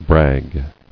[brag]